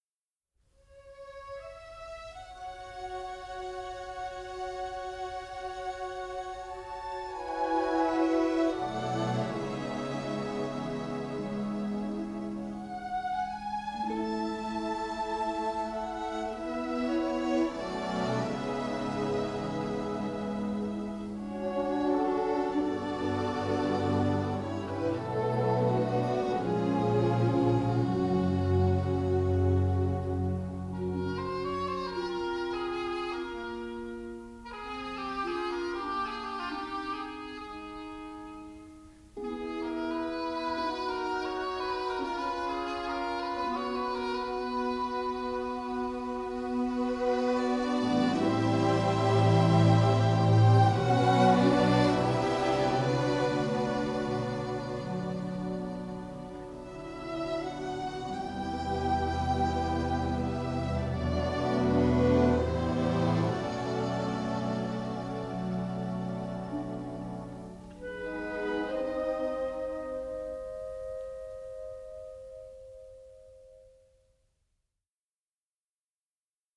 Score recorded at Shepperton Studios in England